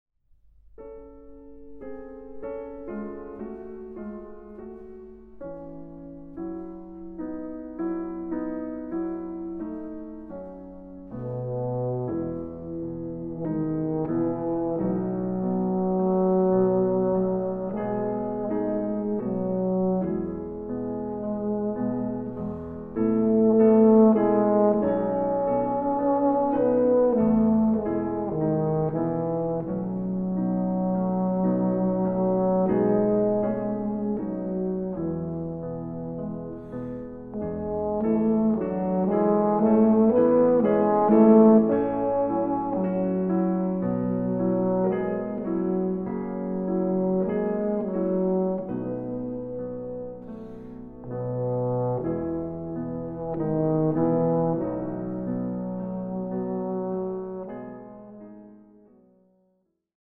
Arr. for Alphorn and Piano